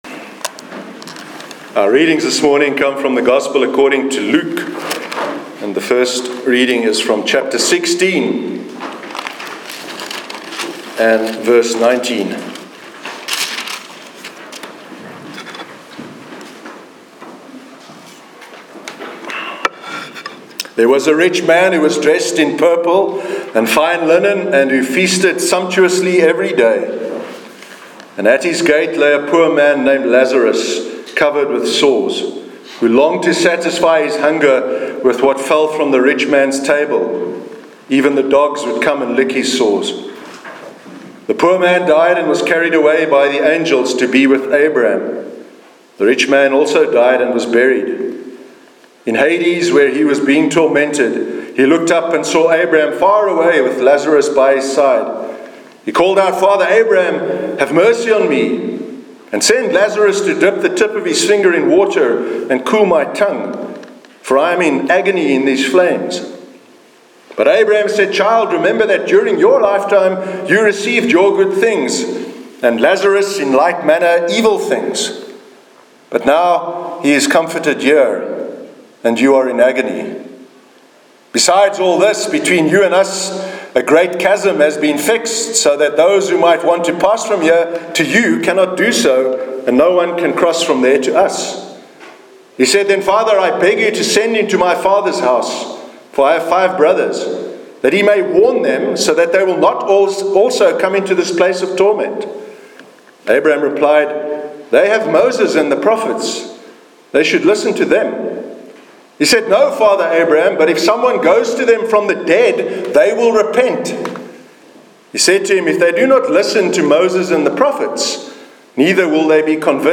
Sermon on the parables of the Rich Man and Lazarus and the Rich Fool- 8th October 2017
sermon_8th_october_2017.mp3